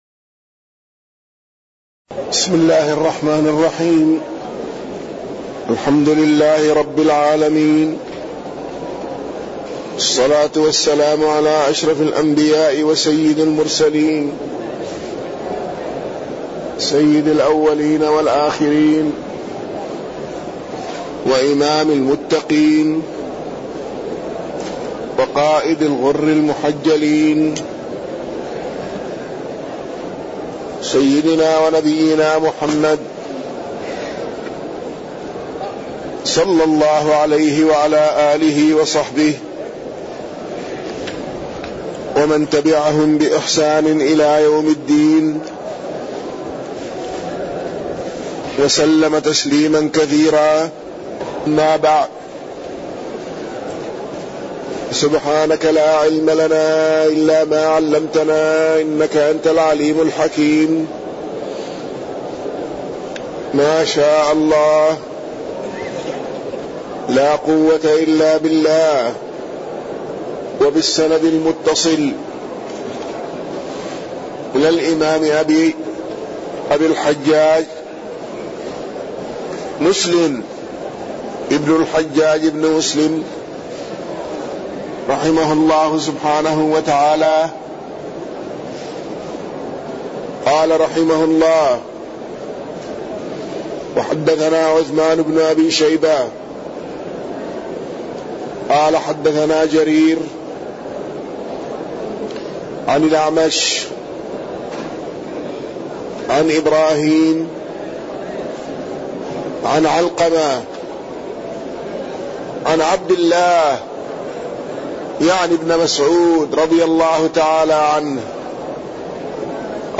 تاريخ النشر ١٩ ربيع الثاني ١٤٣١ هـ المكان: المسجد النبوي الشيخ